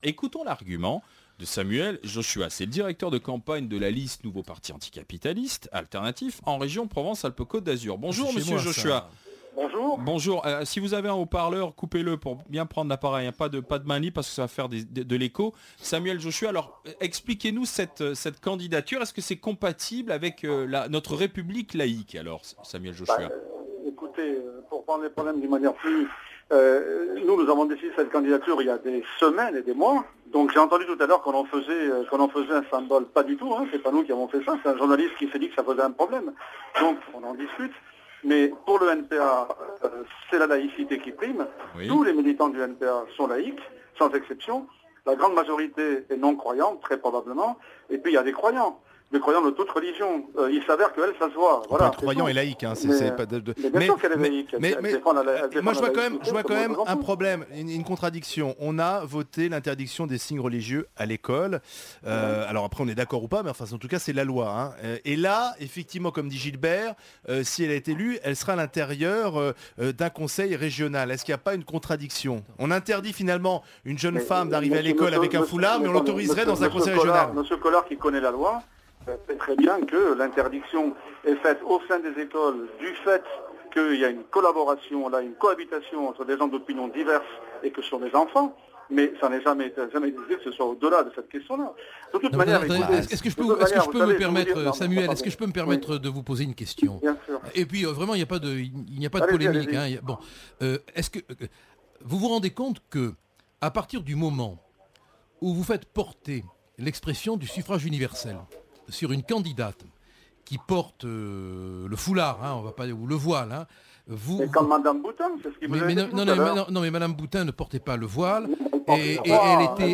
Entretien radiophonique diffusée sur Le blog des Grandes Gueules (émission de RMC), le 3 février 2010.